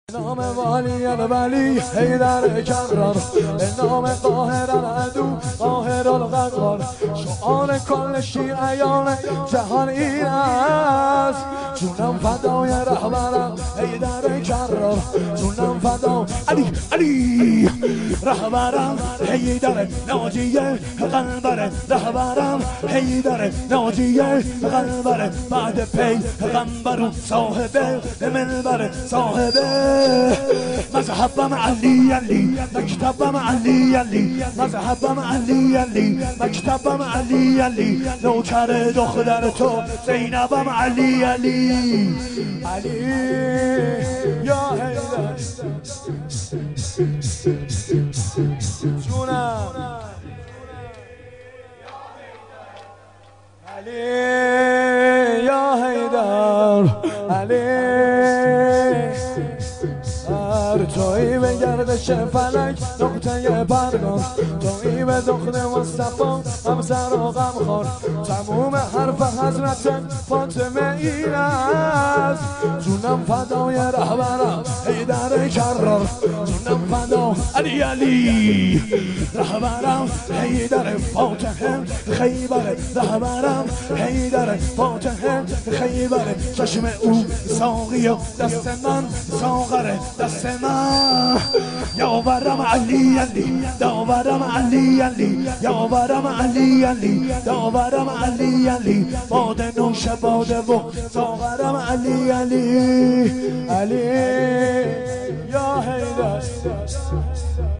شور
شب هفتم محرم ۱۴۴۱